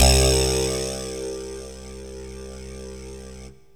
SYNTH GENERAL-4 0004.wav